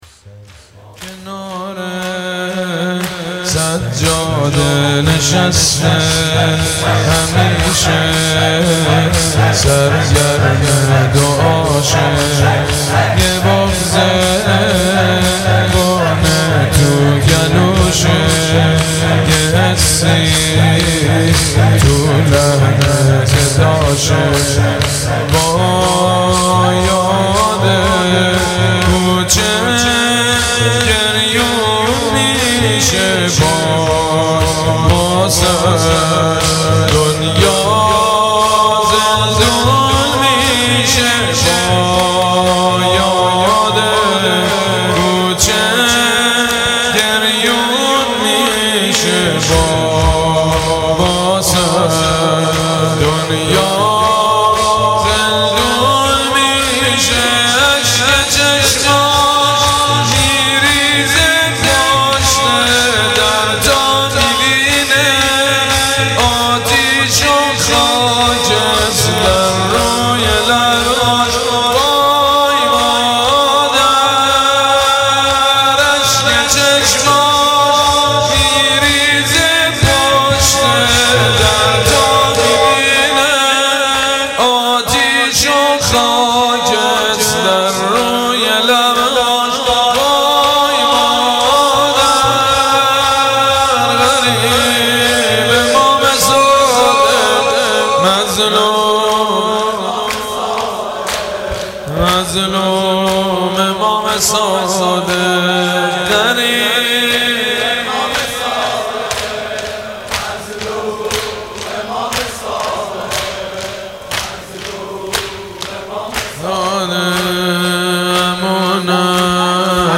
سبک مداحی شهادت امام صادق